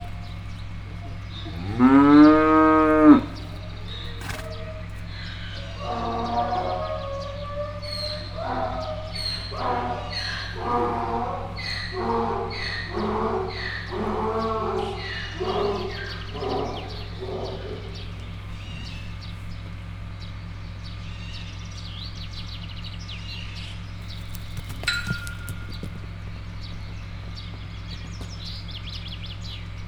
Directory Listing of /_MP3/allathangok/miskolcizoo2018_professzionalis/magyar_tarka_szarvasmarha/
magyartarka_kulan_miskolczoo0029.WAV